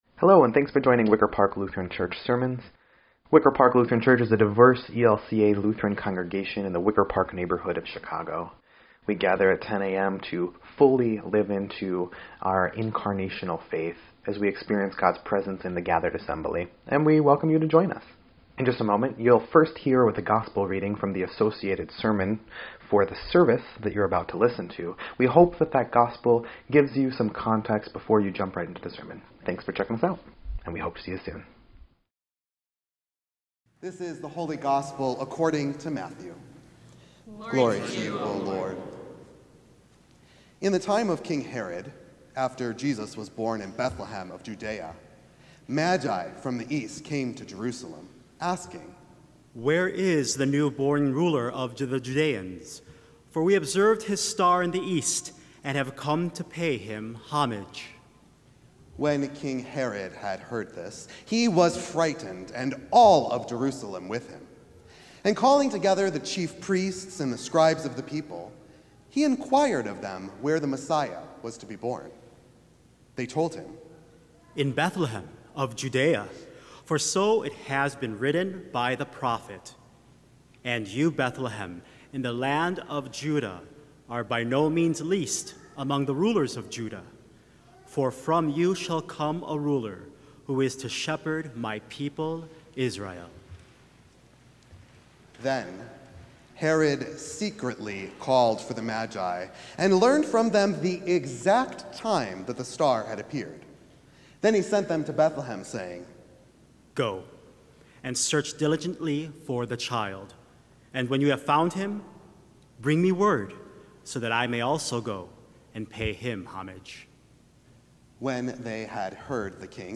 1.11.26-Sermon_EDIT.mp3